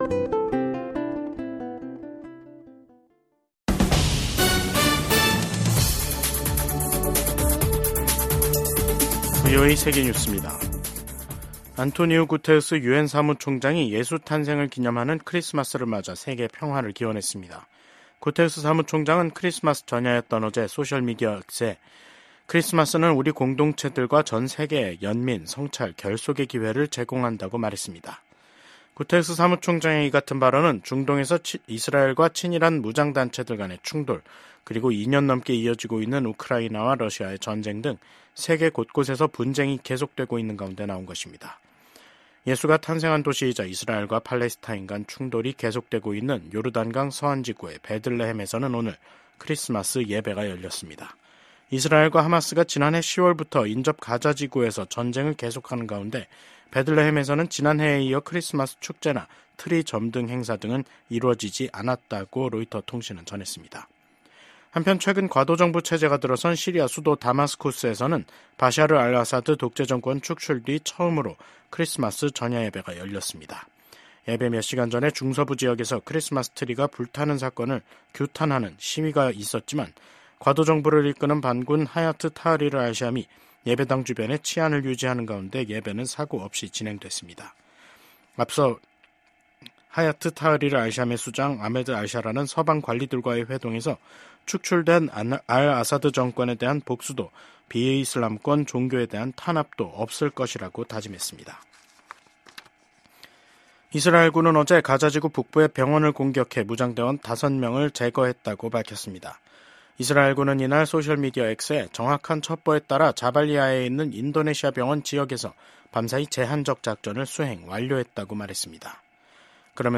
VOA 한국어 간판 뉴스 프로그램 '뉴스 투데이', 2024년 12월 25일 2부 방송입니다. 한국의 계엄과 탄핵 사태로 윤석열 대통령이 미국과 공조해 추진해 온 미한일 3국 협력이 지속 가능하지 않을 수 있다고 미 의회조사국이 평가했습니다. 미국과 한국 정부가 한국의 비상계엄 사태로 중단됐던 양국의 외교안보 공조 활동을 재개키로 했습니다. 일본에서 발생한 대규모 비트코인 도난 사건이 북한 해커집단의 소행으로 밝혀졌습니다.